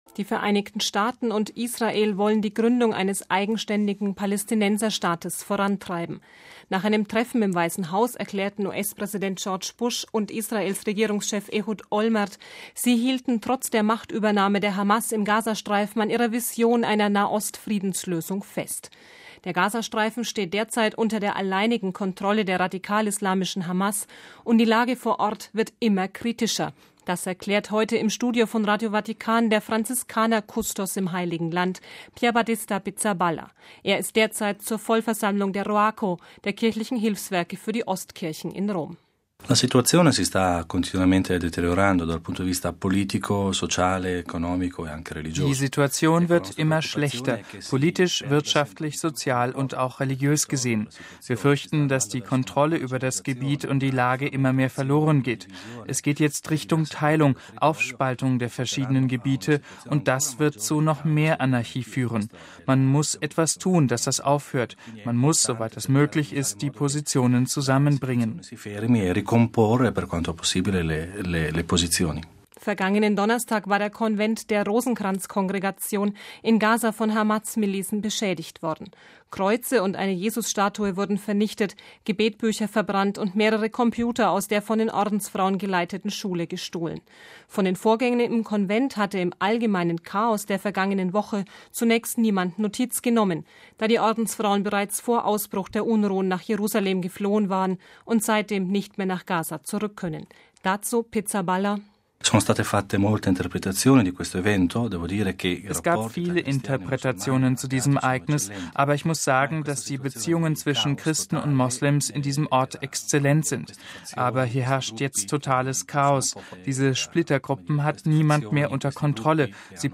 Der Gazastreifen steht derzeit unter der alleinigen Kontrolle der radikal-islamischen Hamas und die Lage vor Ort wird immer kritischer, erklärte heute im Studio von Radio Vatikan der Franziskanerkustos im Heiligen Land, Pierbattista Pizzaballa OFM.